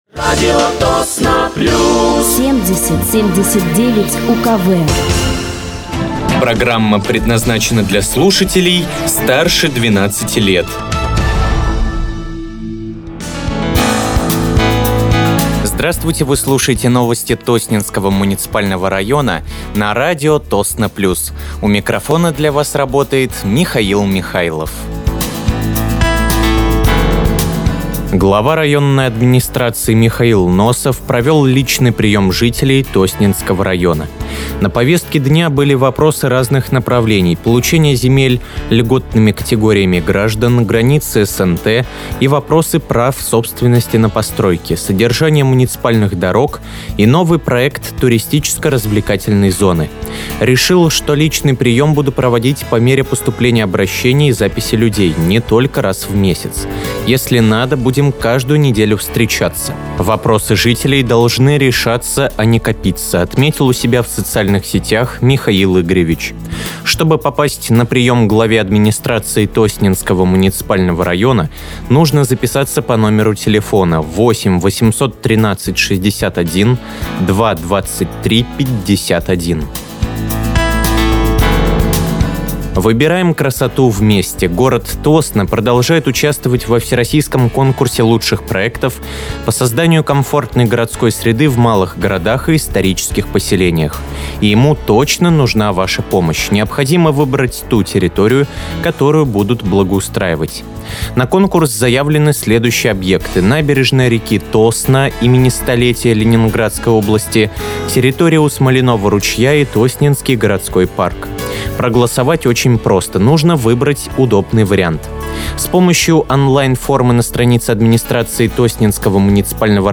Выпуск новостей Тосненского муниципального района от 04.12.2025